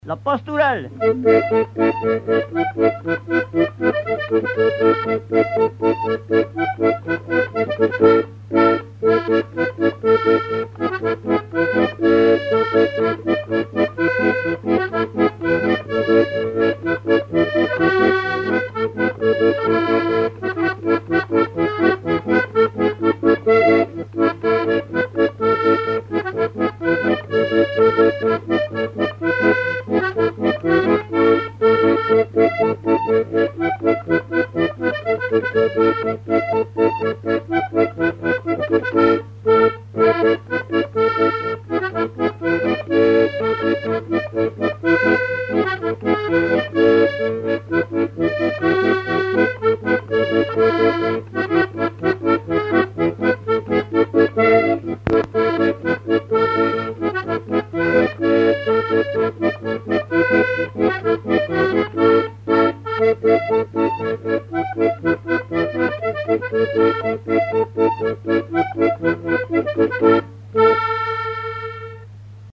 Mémoires et Patrimoines vivants - RaddO est une base de données d'archives iconographiques et sonores.
Quadrille - Pastourelle
danse : quadrille : pastourelle
Pièce musicale inédite